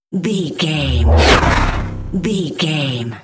Scifi whoosh pass by
Sound Effects
futuristic
pass by
car
vehicle